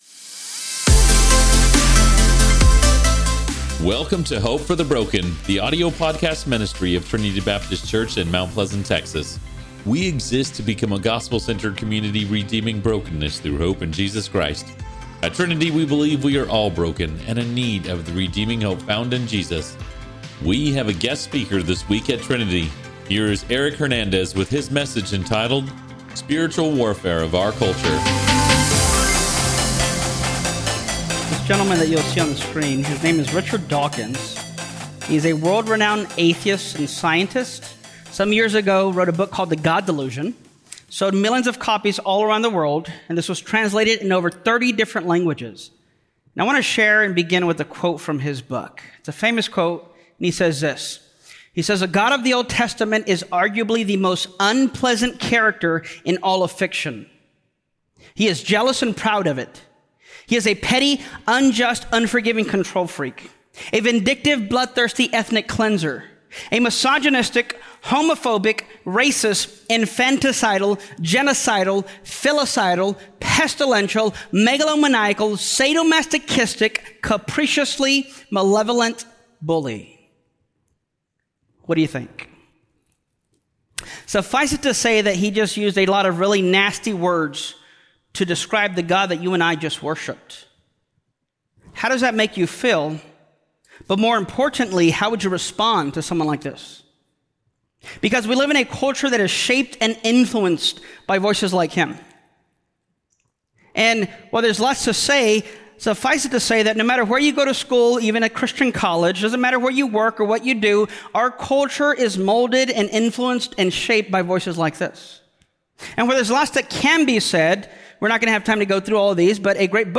Stand-alone Sermons